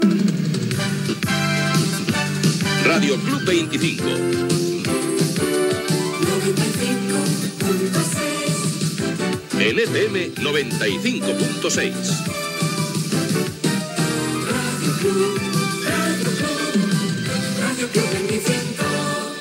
Indicatiu de l 'emissora